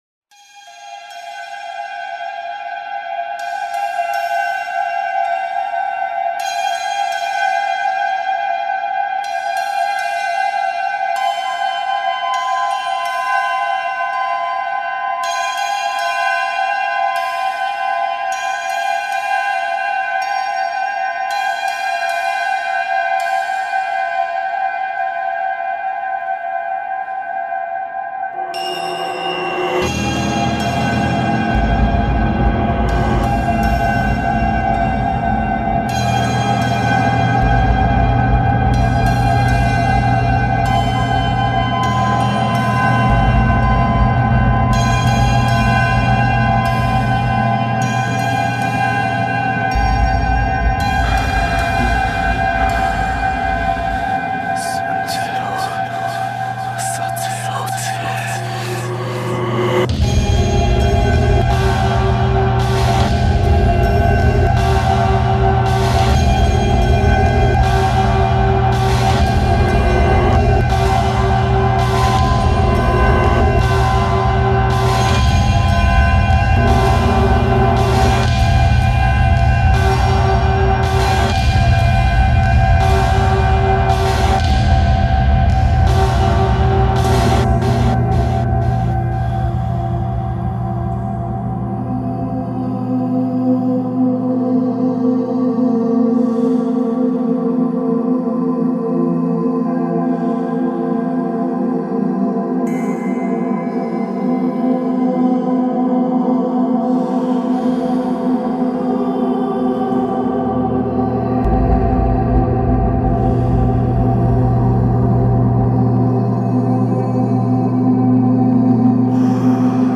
(ritual/dark ambient)